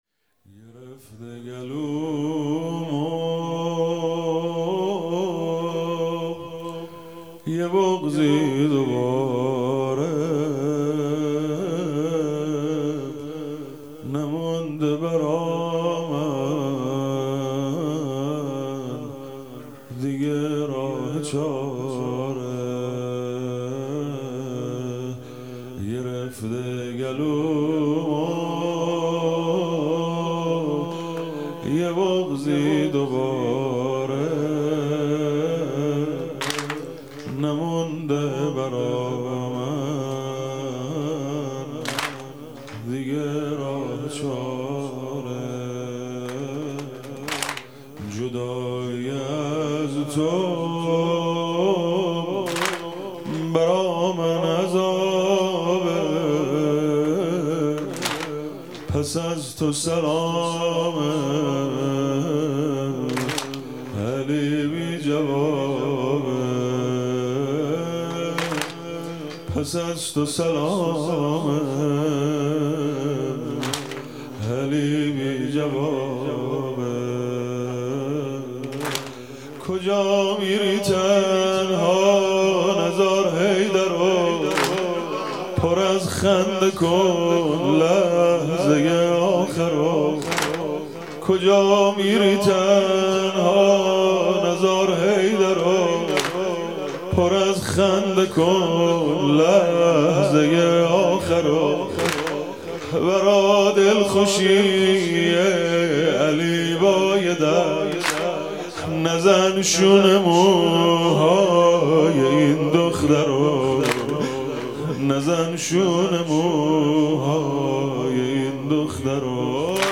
مداحی شهادت حضرت فاطمه زهرا ( س )
شب هشتم دهه فاطمیه، ۱۴ دی ۱۴۰۰